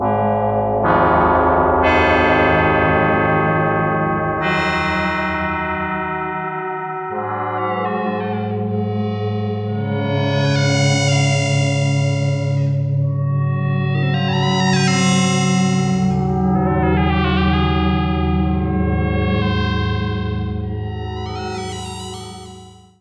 FLAME "FM KOSMOS" Quad polyphonic FM synthesizer
11 - Spooky Pad
11_SpookyPad.wav